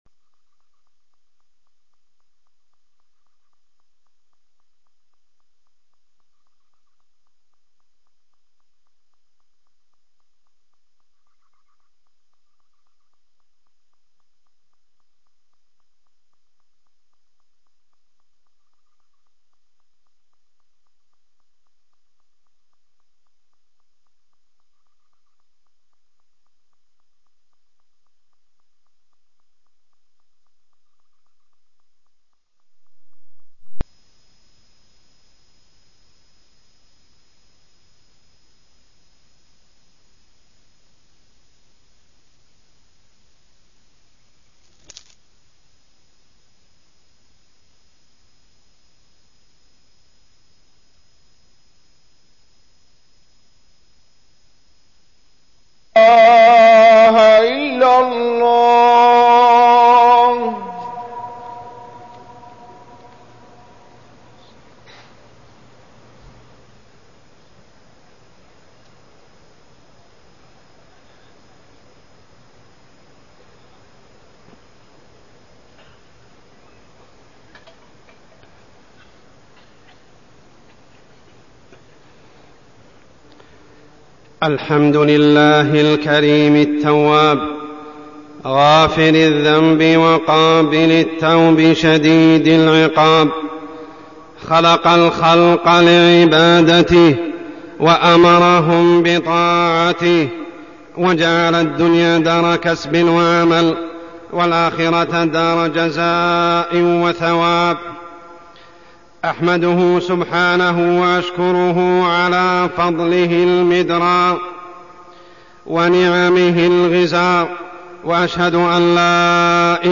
تاريخ النشر ١١ صفر ١٤١٩ هـ المكان: المسجد الحرام الشيخ: عمر السبيل عمر السبيل حب الدنيا والشهوات